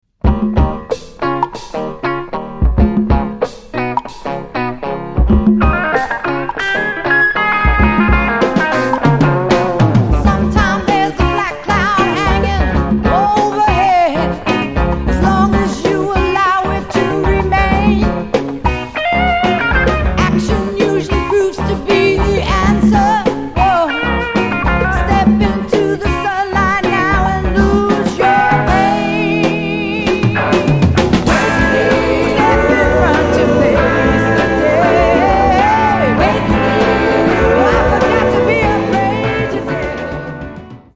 SWAMP ROCK